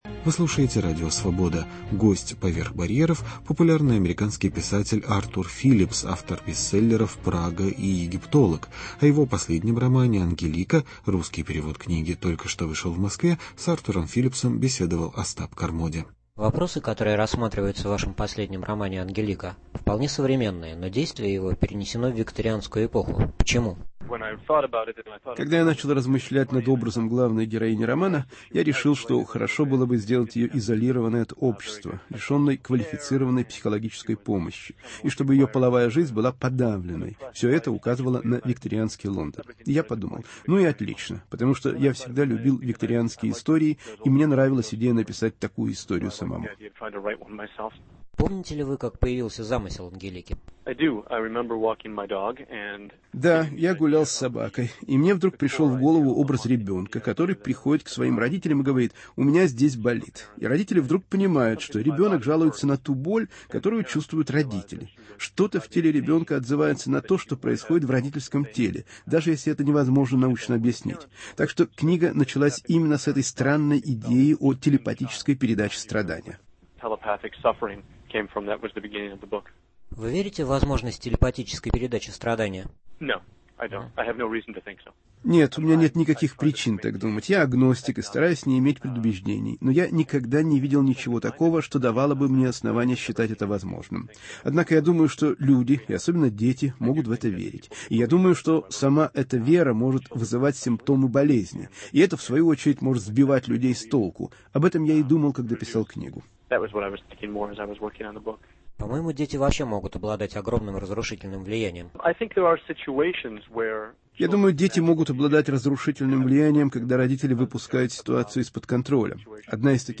Интервью с американским писателем Артуром Филипсом